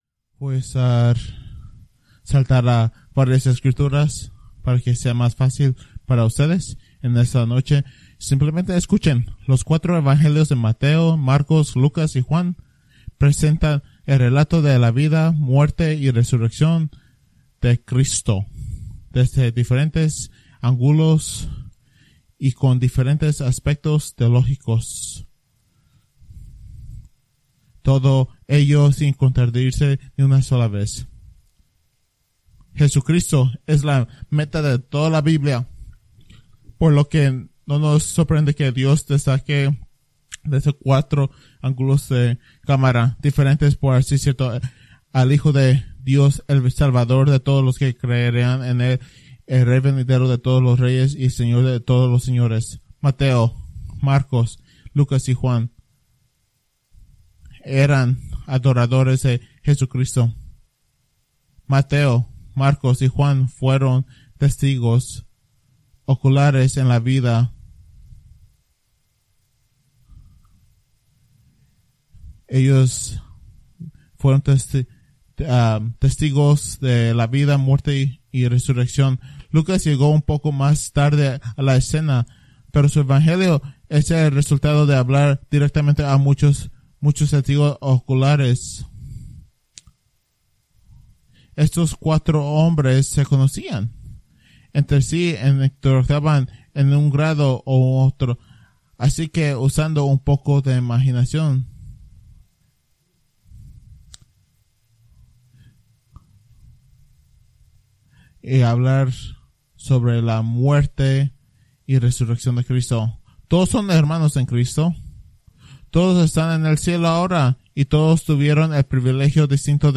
Preached March 29, 2024 from Escrituras seleccionadas